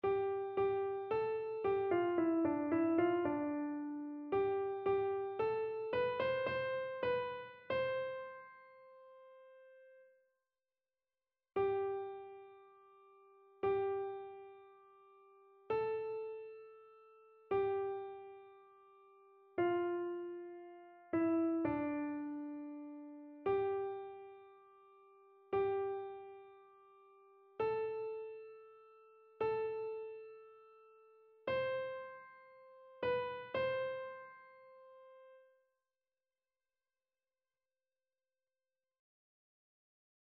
Soprano
annee-abc-temps-pascal-ascension-du-seigneur-psaume-46-soprano.mp3